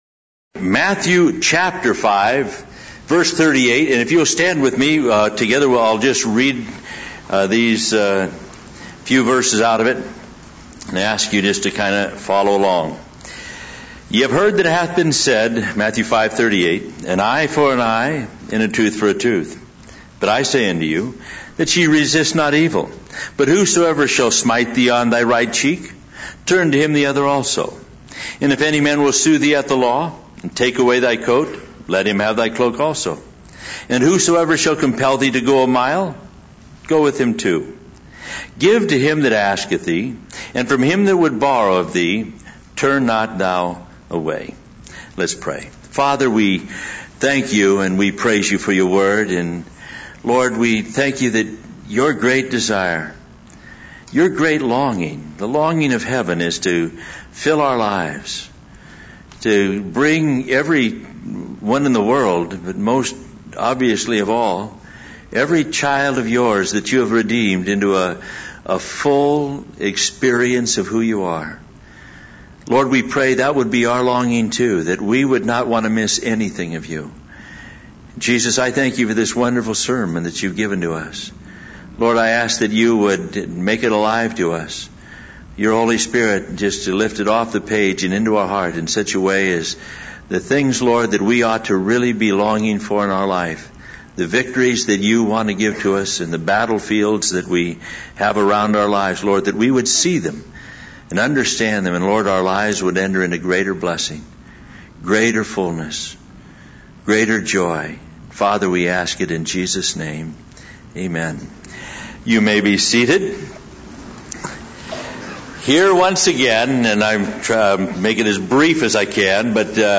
Ultimately, the sermon calls for a deeper commitment to living out the principles of the Beatitudes in everyday interactions.